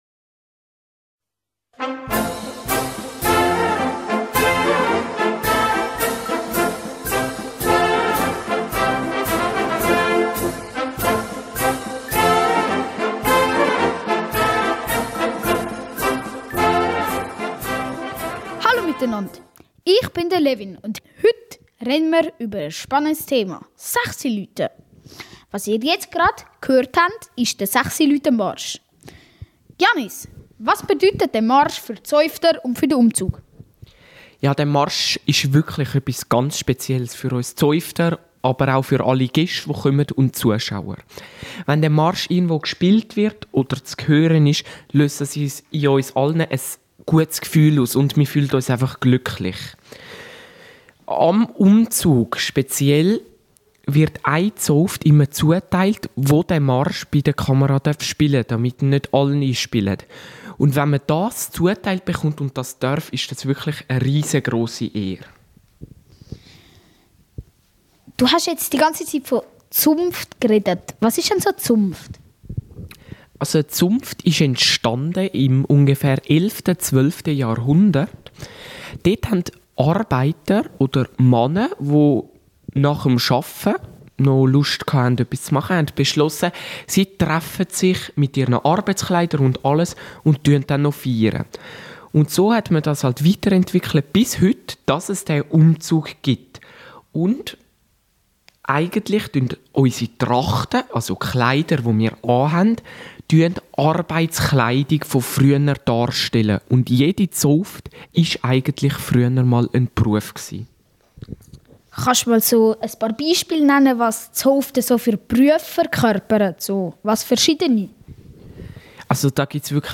Euch erwartet ein Experte in diesem Gebiet.